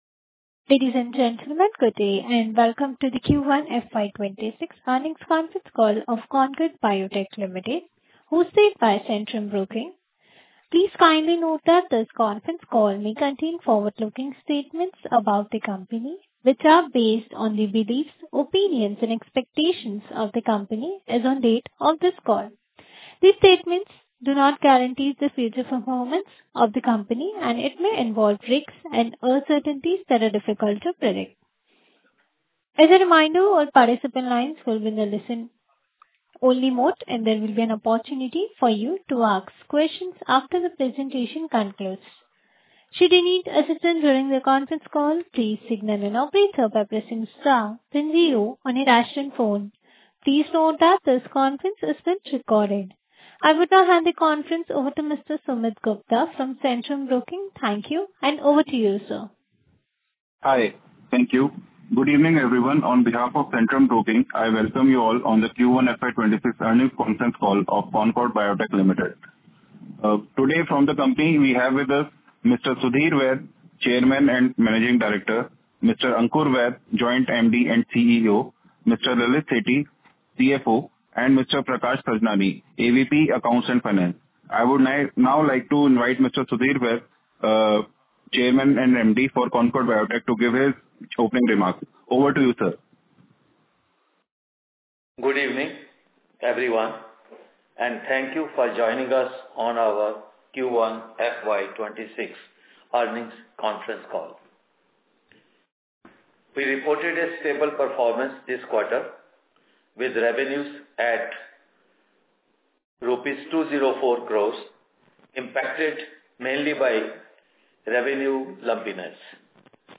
The company recently held its earnings call for the first quarter ended June 30, 2025, shedding light on its performance and future outlook.
concord-Q1FY26-earnings-call-audio.mp3